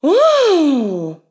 Exclamación de mujer: ¡Guau!
interjección
mujer
sorpresa
Sonidos: Acciones humanas
Sonidos: Voz humana